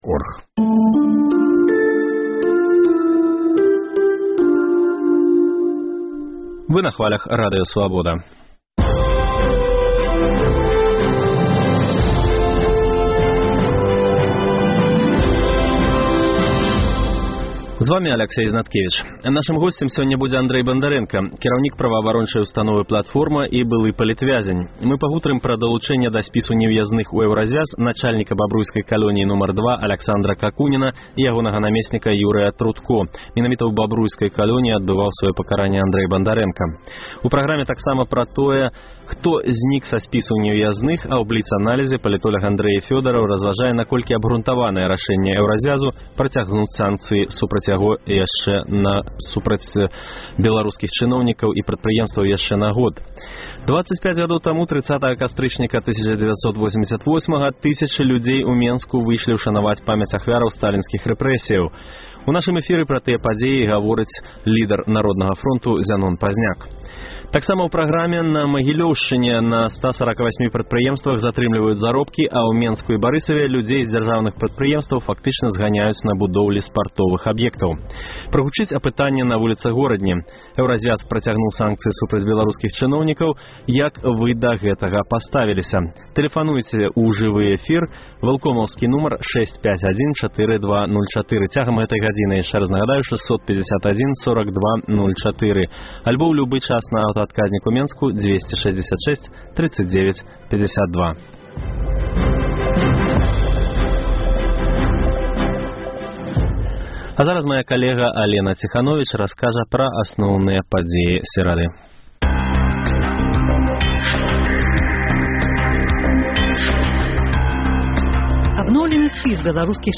25 гадоў таму, 30 кастрычніка 1988 году, тысячы людзей у Менску выйшлі ўшанаваць памяць ахвяраў сталінскіх рэпрэсіяў. У нашым эфіры пра тыя падзеі гаворыць Зянон Пазьняк. Таксама ў праграме – на Магілёўшчыне на 148 прадпрыемствах затрымліваюць заробкі, а ў Менску і Барысаве людзей зь дзяржаўных прадпрыемстваў фактычна зганяюць на будоўлі спартовых аб'ектаў.